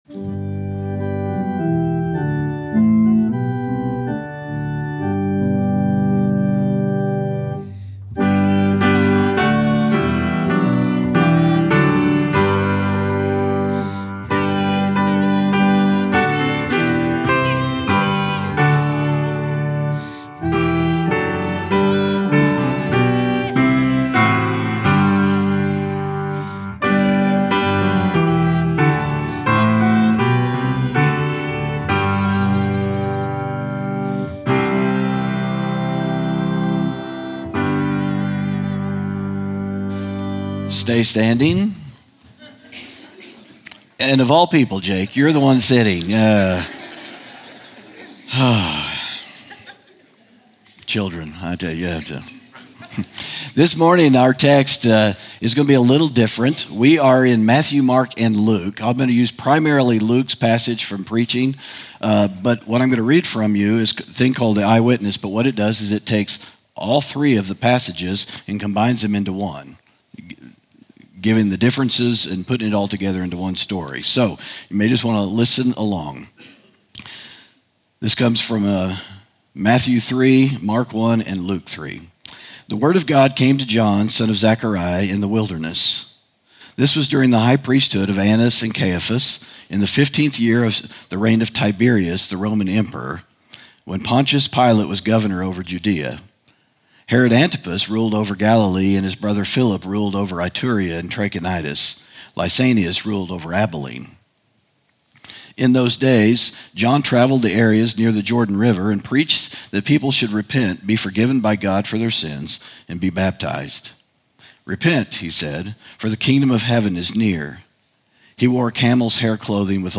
sermon audio 0104.mp3